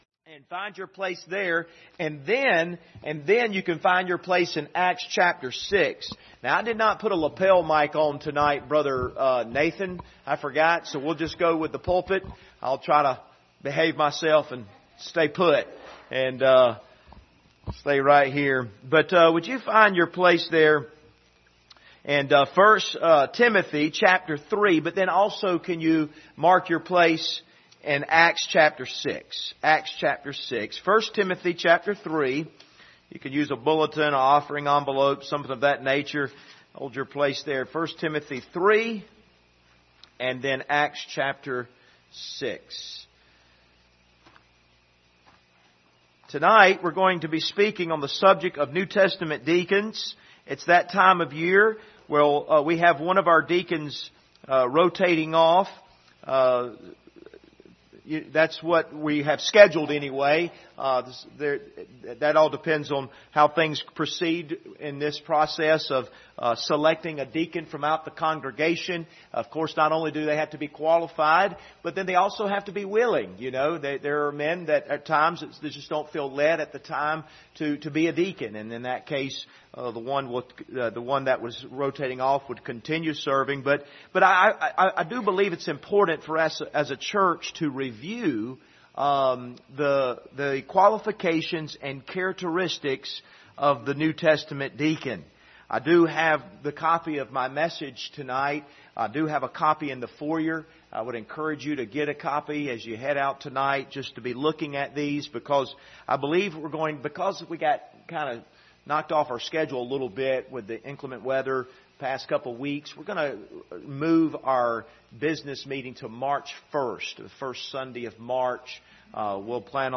Passage: Acts 6:1-5, 1 Timothy 3:8-13 Service Type: Sunday Evening Topics